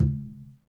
Tumba-HitN_v1_rr2_Sum.wav